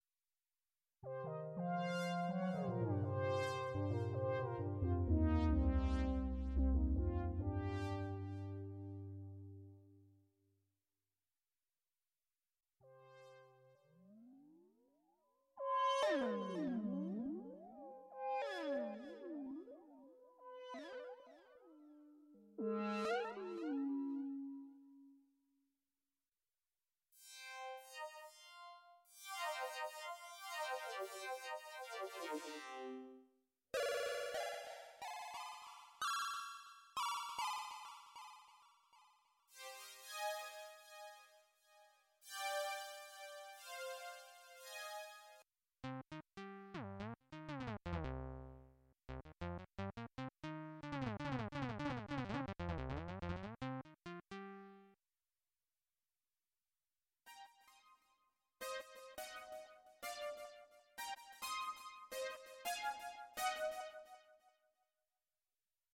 NLog Synth iPad Demo